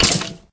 sounds / mob / skeleton / hurt2.ogg
hurt2.ogg